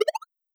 GenericNotification9.wav